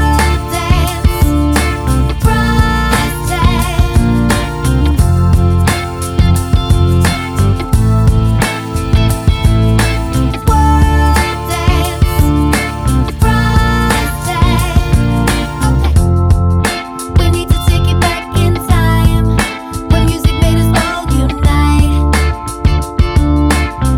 no Rap Pop (2010s) 3:42 Buy £1.50